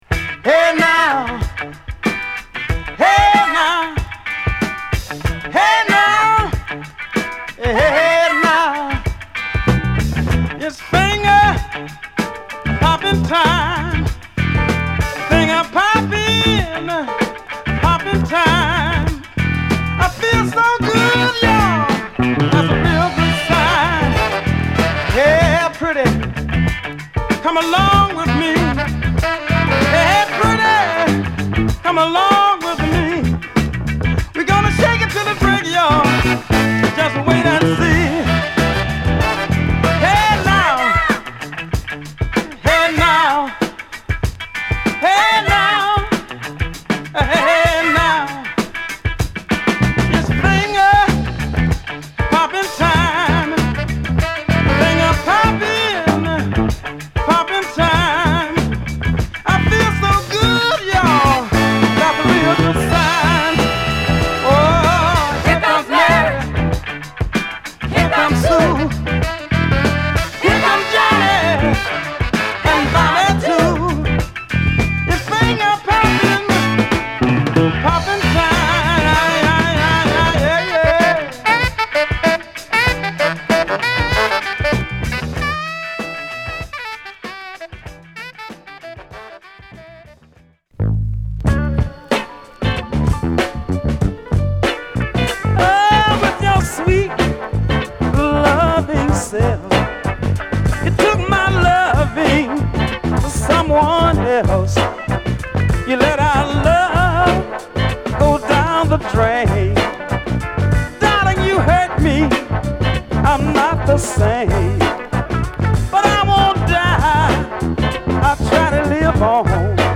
乾いたカッティング/ピアノに厚みあるブラス隊が支える、派手さ控え目の渋いミディアム・ファンク！
(Mono)